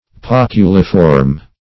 Search Result for " poculiform" : The Collaborative International Dictionary of English v.0.48: Poculiform \Poc"u*li*form\, a. [L. poculum a cup + -form: cf. F. poculiforme.] Having the shape of a goblet or drinking cup.